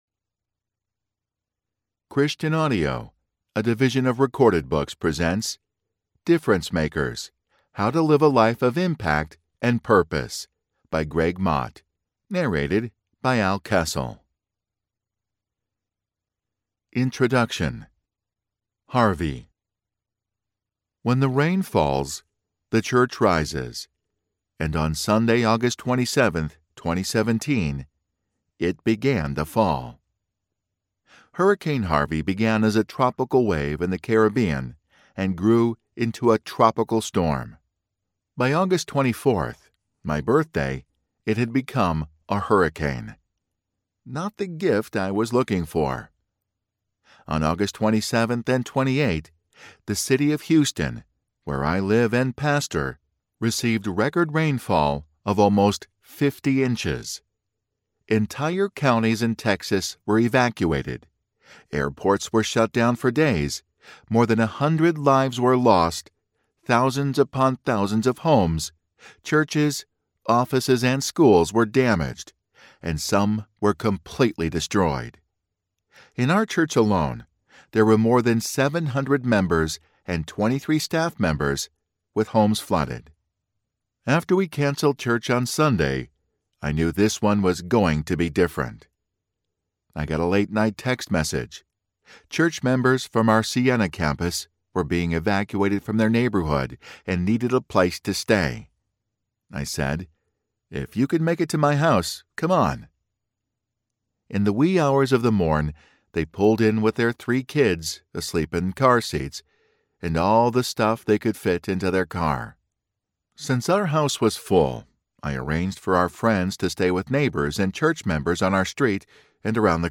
Difference Makers: How to Live a Life of Impact and Purpose Audiobook
Narrator
4.46 Hrs. – Unabridged